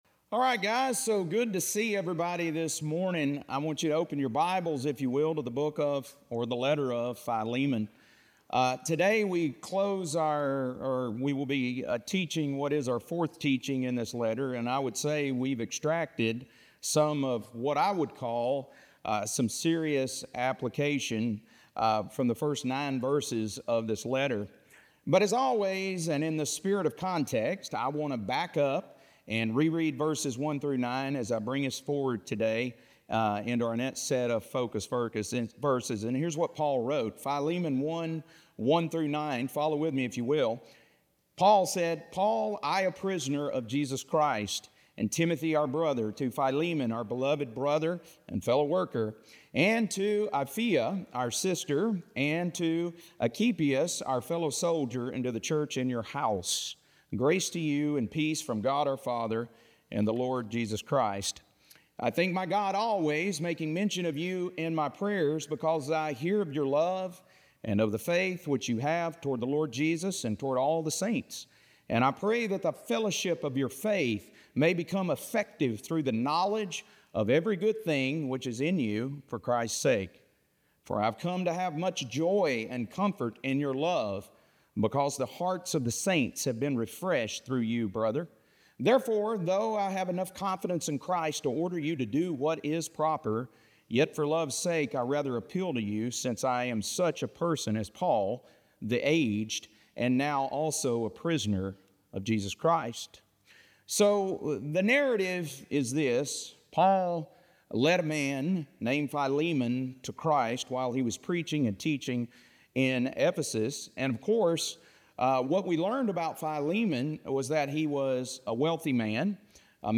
Audio Taught